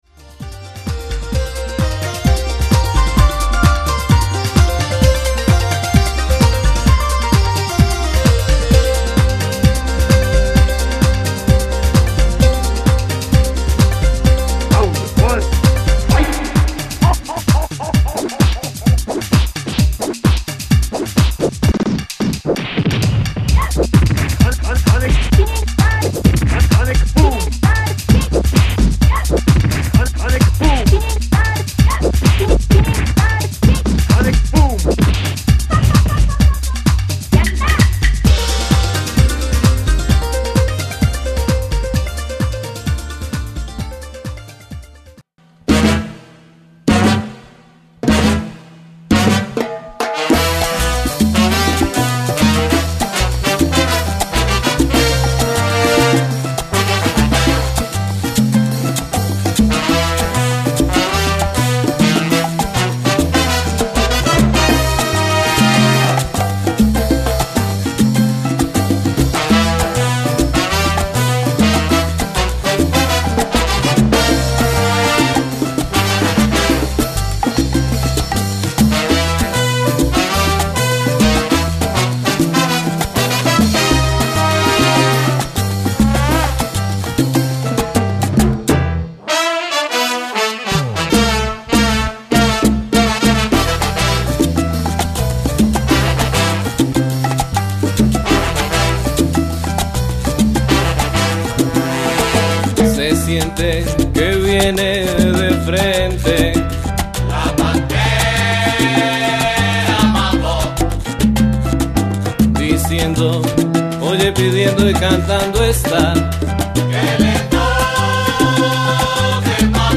Attachment '효과음-때리는소리.mp3'